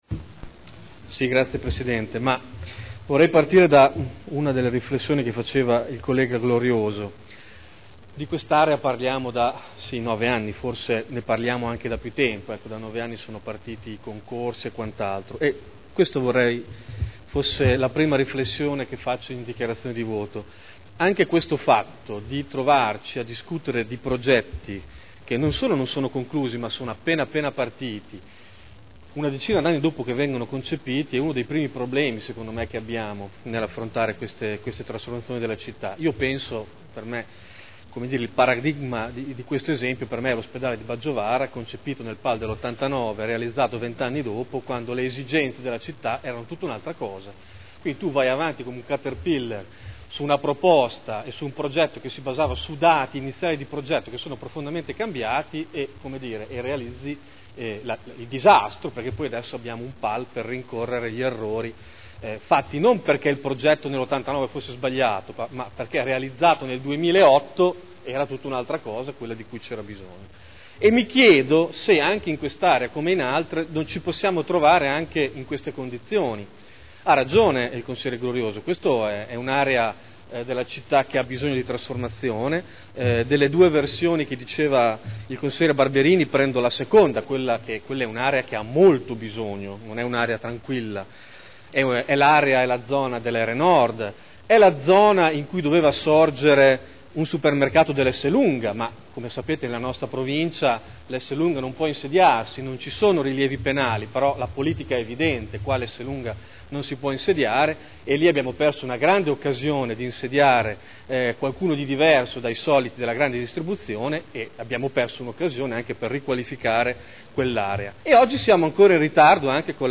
Davide Torrini — Sito Audio Consiglio Comunale